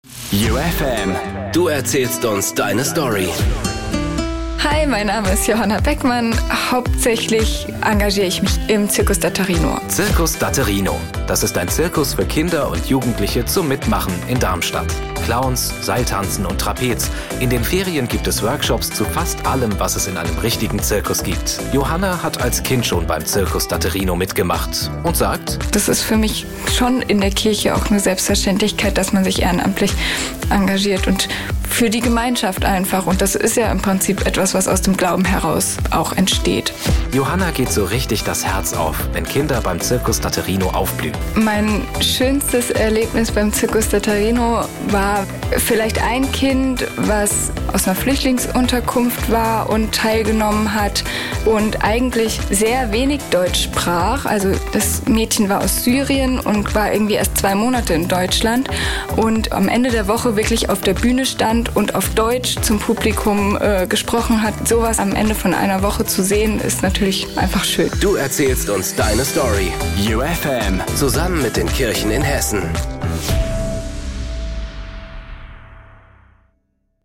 Das YOU FM Portrait